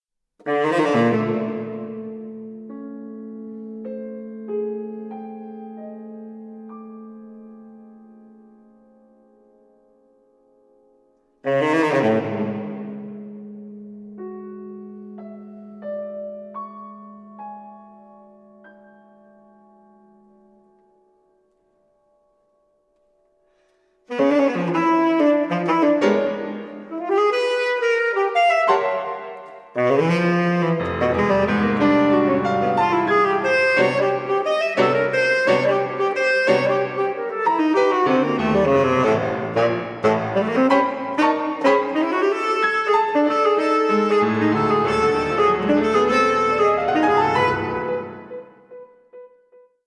saxophones
piano